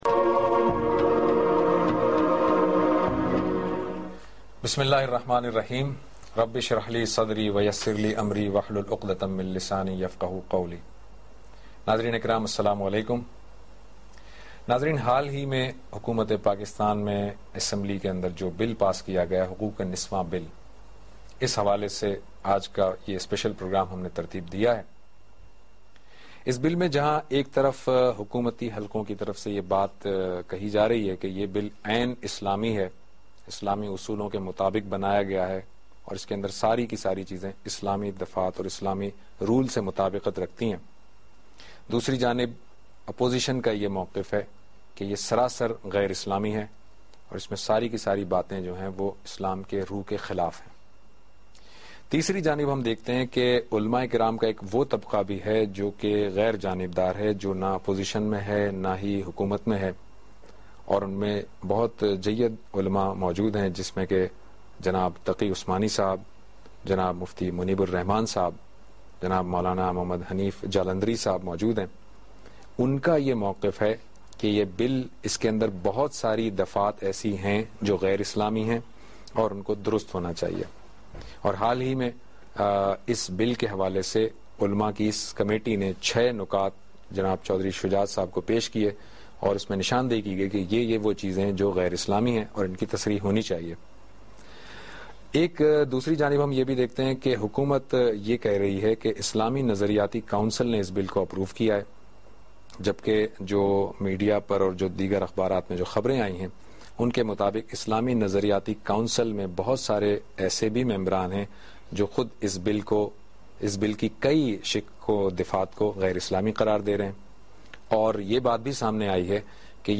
Discussion about Woman Protection Bill in Pakistan with Javed Ahmad Ghamidi. A special program on Aaj TV.